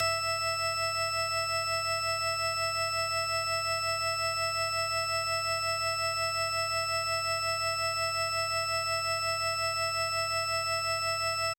DreChron GameSynth.wav